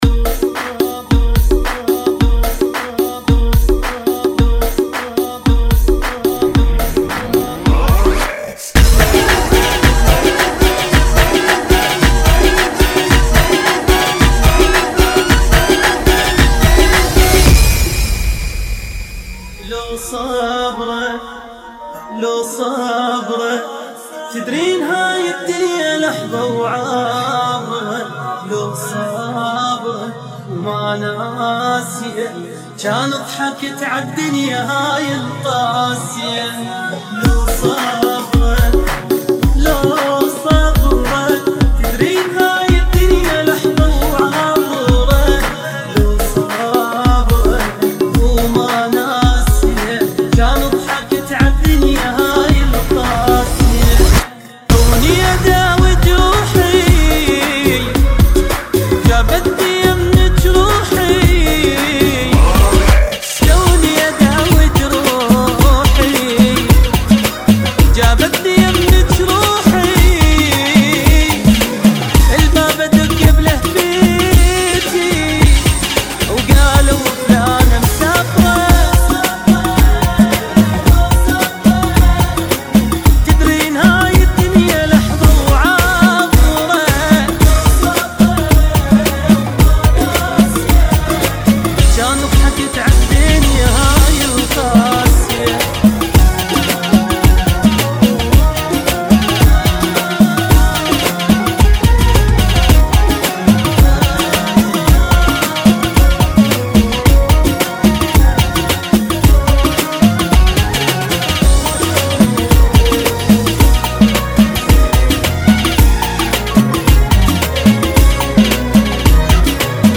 EDIT ] - [ 110 BPM